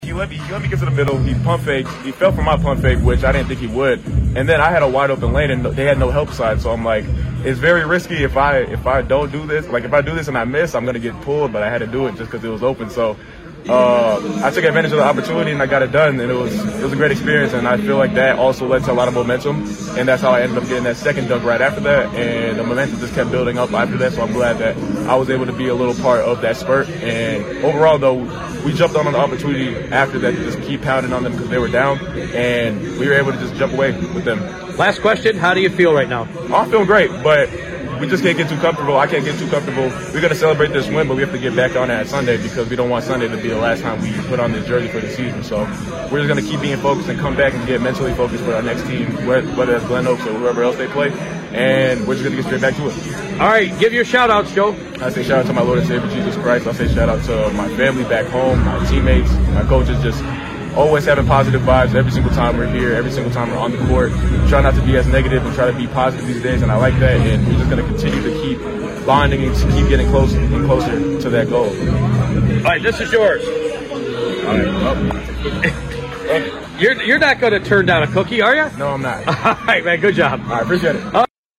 post-game coimments